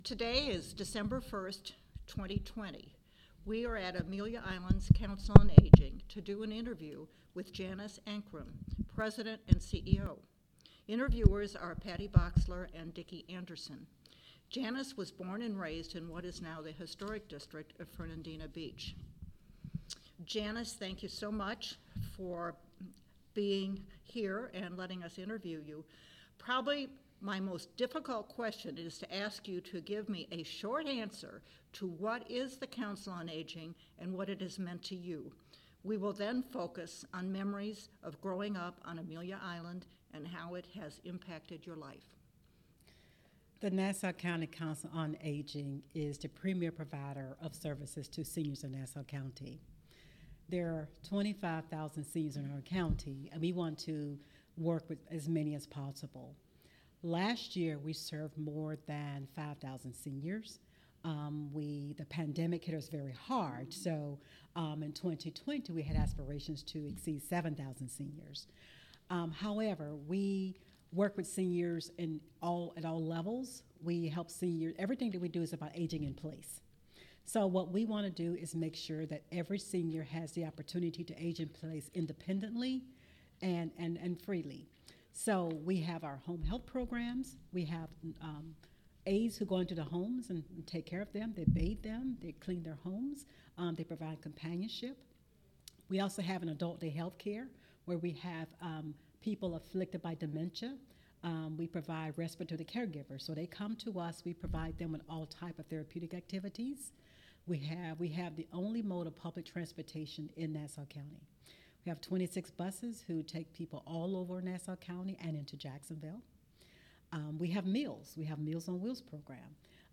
Click Here to play the Oral History Recording.